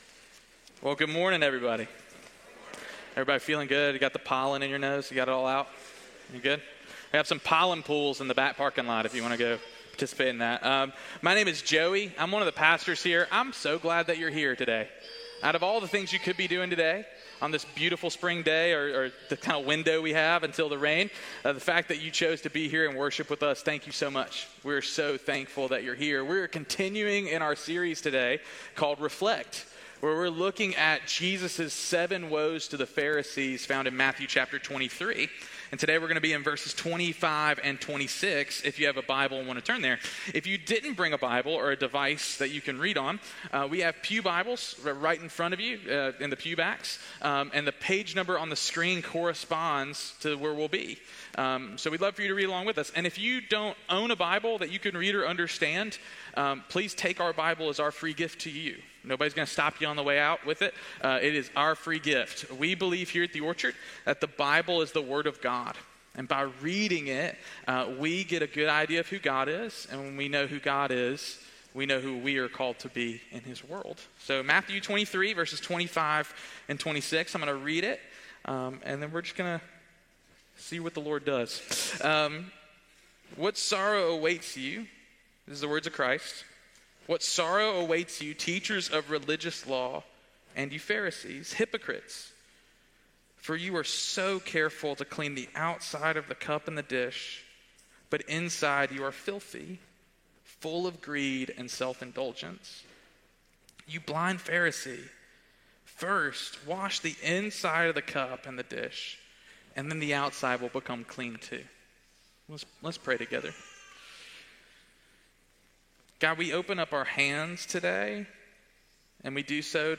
Sermon Series: Reflect